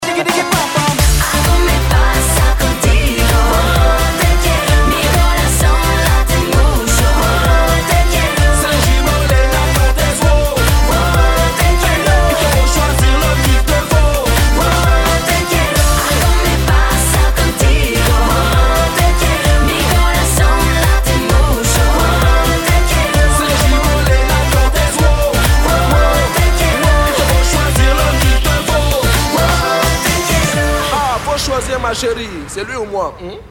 • Качество: 192, Stereo
латина